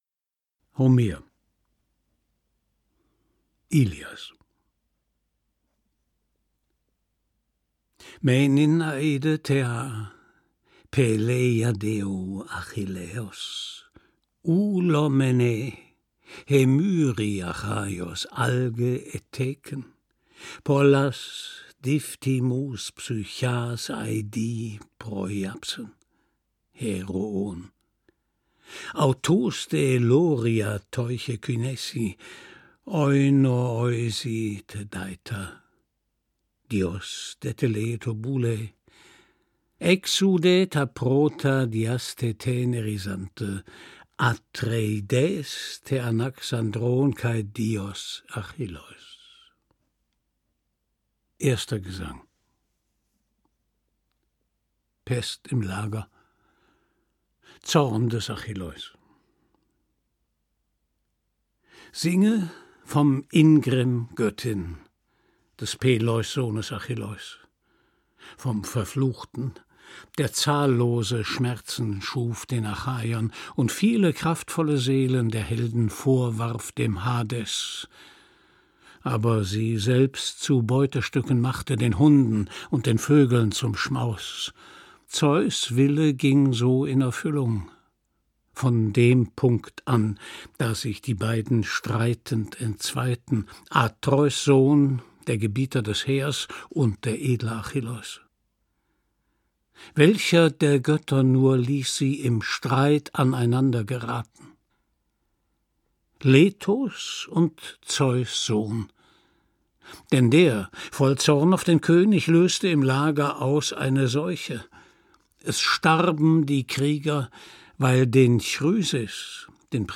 Gekürzt Autorisierte, d.h. von Autor:innen und / oder Verlagen freigegebene, bearbeitete Fassung.
Ilias Gelesen von: Christian Brückner